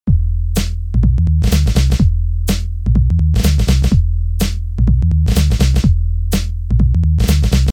描述：在Ableton中用我的Push制作的简单低音线。
使用的效果：Major Wah，Strings Submix，和一点点混响。
标签： 100 bpm Grime Loops Bass Loops 1.62 MB wav Key : Unknown
声道立体声